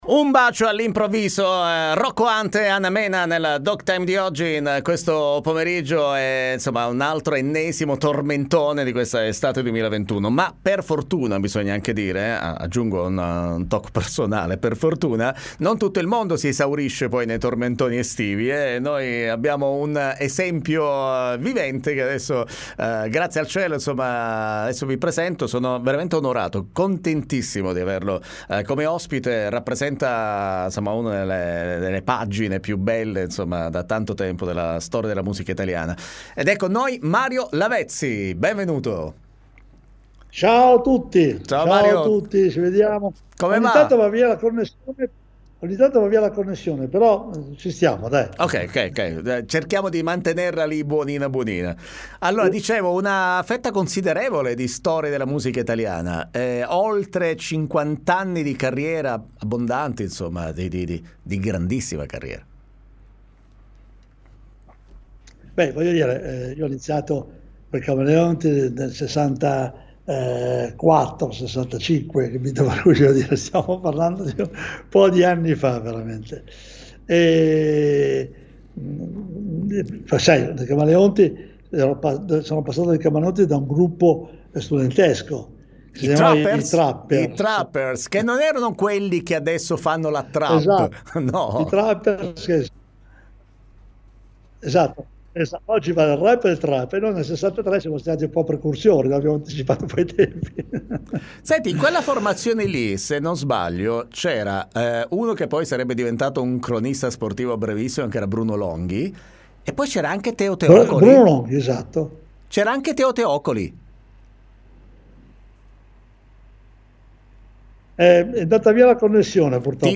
D.T. Intervista Mario Lavezzi
D.T.-Intervista-Mario-Lavezzi.mp3